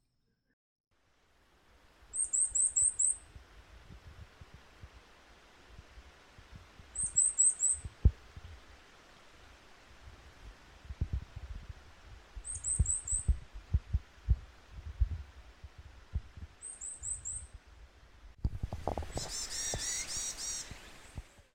• ほか動物の声（屋久島にて収録）
ヤマガラ